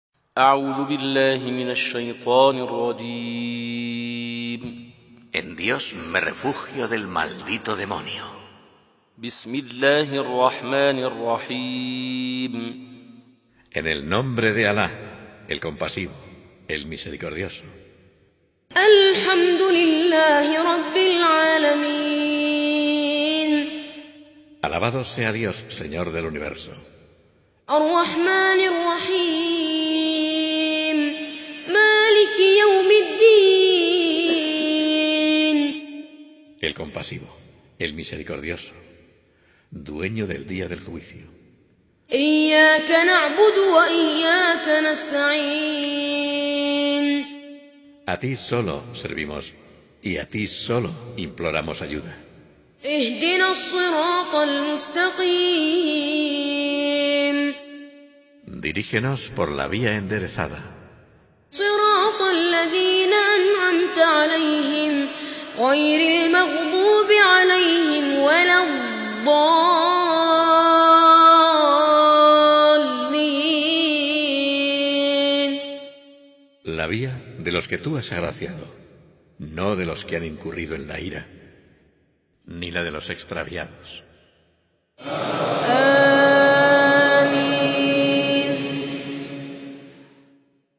قرآن کریم – el Corán | El noble Corán recitado en Árabe, Español y Persa – قرائت قرآن مجید با ترجمۀ اسپانیایی و فارسی